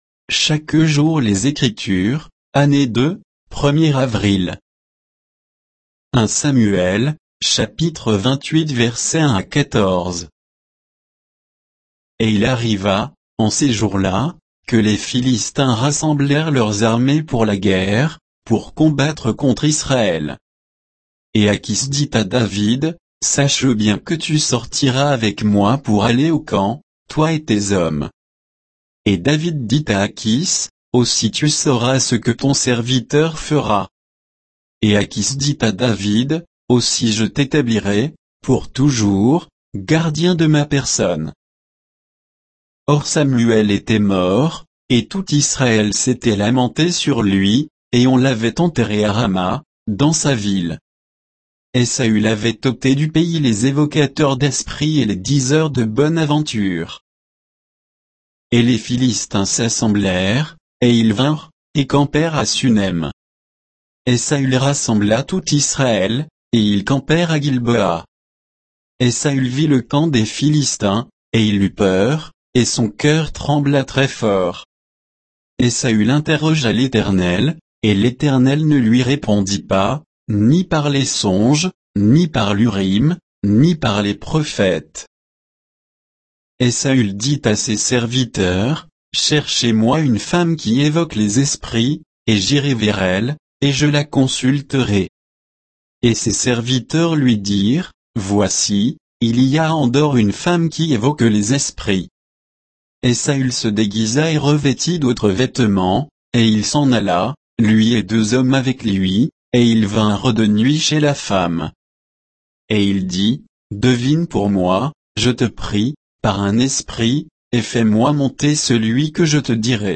Méditation quoditienne de Chaque jour les Écritures sur 1 Samuel 28